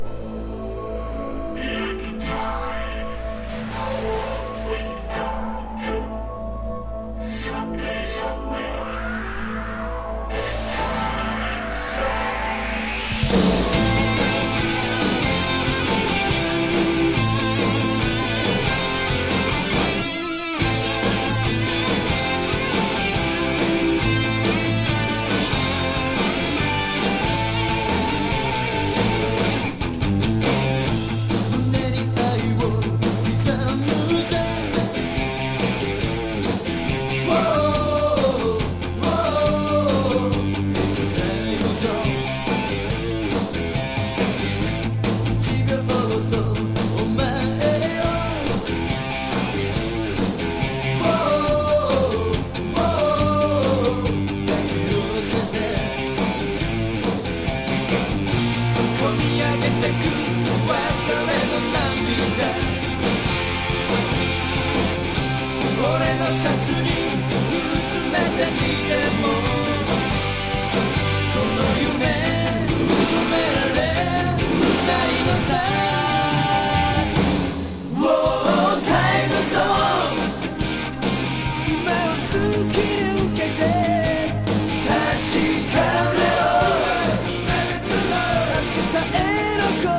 • 盤面 : EX+ (美品) キズやダメージが無く音質も良好